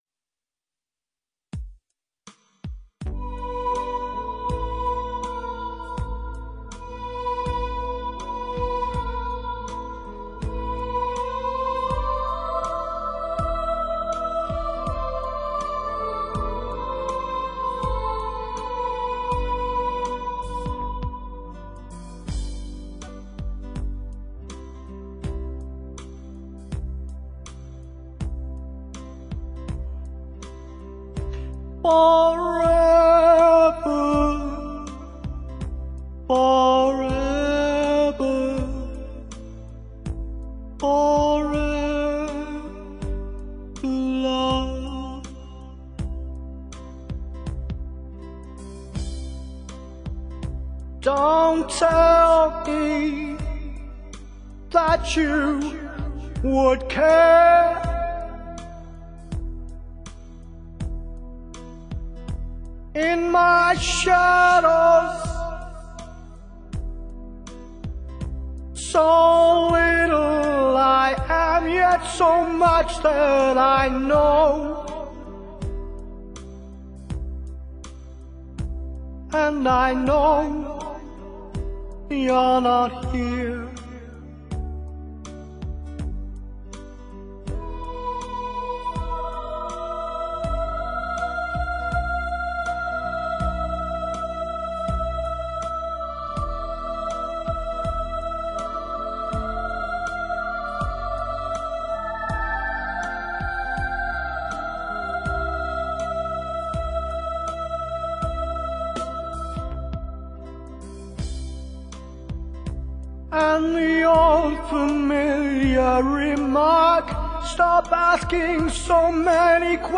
Vocals - Keys